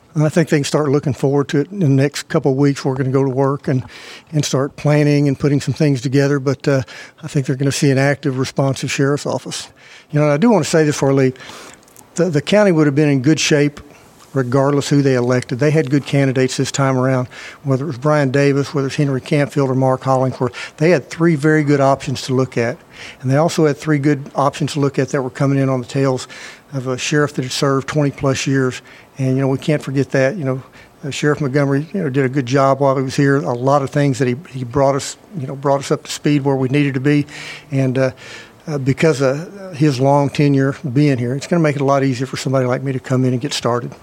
KTLO, Classic Hits and The Boot News spoke with Sheriff elect Hollingsworth live at the Baxter County Courthouse Tuesday evening following the news of his victory.